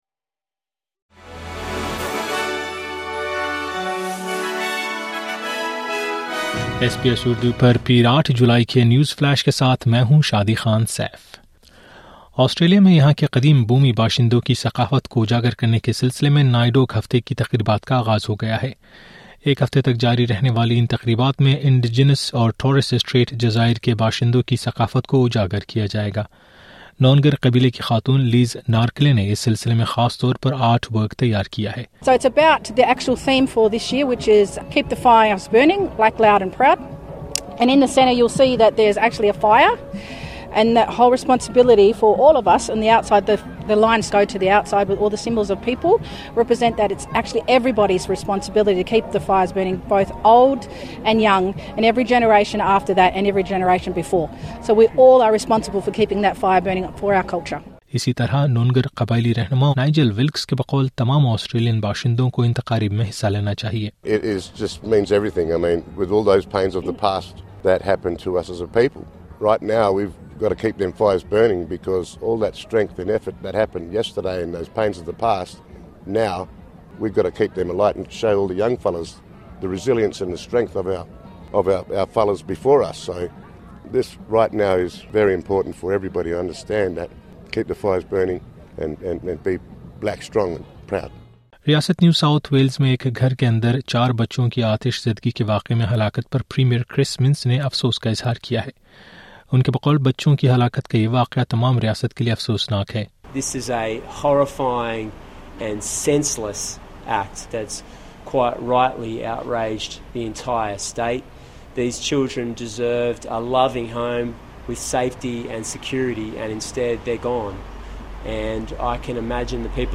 نیوز فلیش 8 جولائی: نائیڈوک ہفتے کی تقریبات، سڈنی میں 4 بچے آتشزدگی میں ہلاک